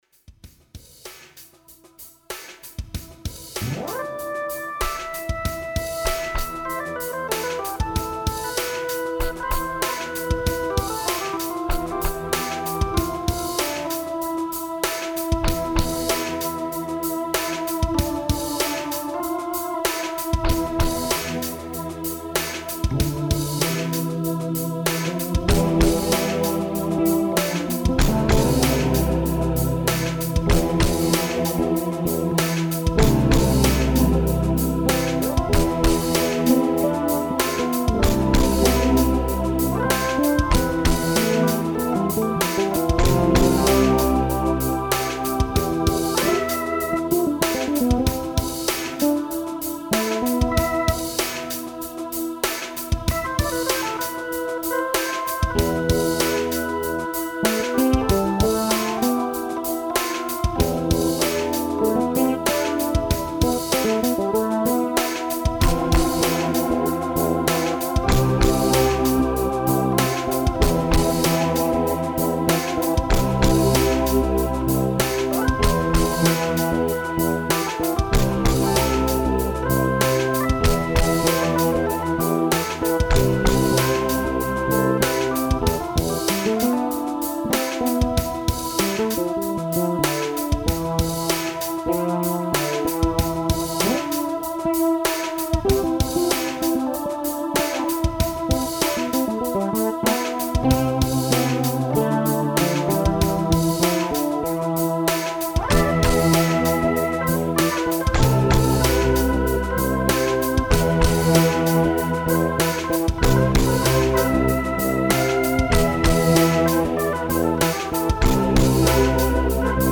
Tempo: 65 bpm / Datum: 26.12.2017